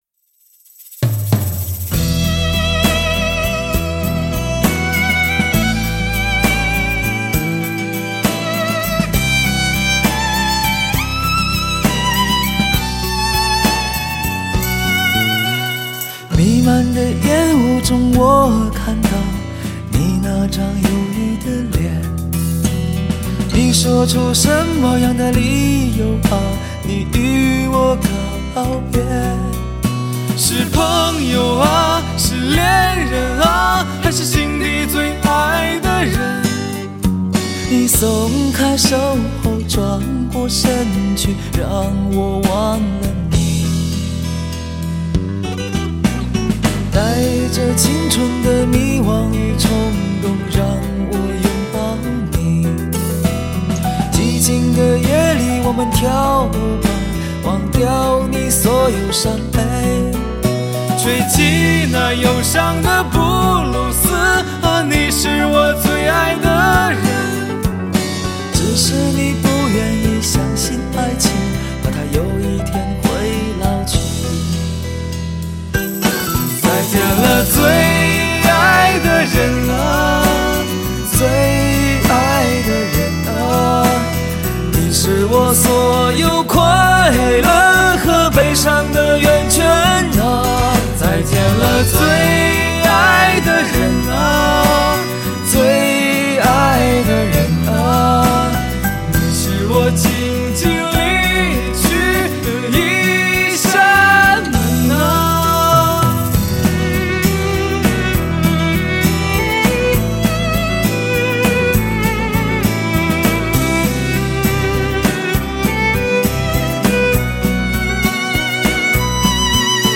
Ps：在线试听为压缩音质节选，体验无损音质请下载完整版 作词